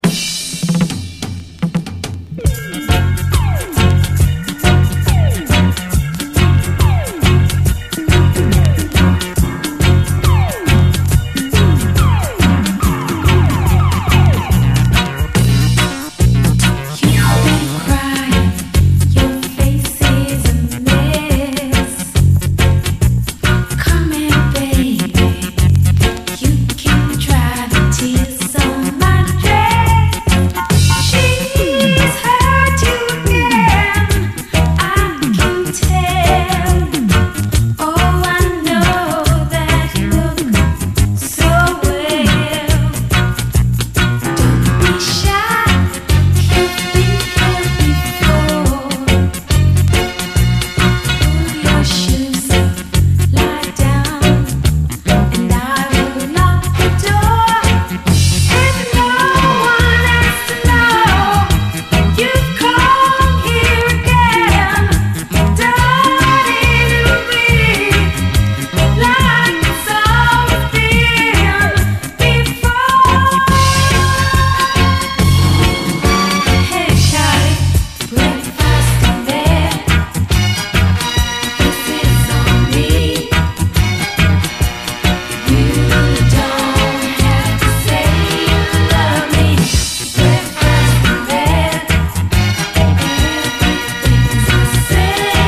いわば90’S版チカーノ・ソウルという感じ。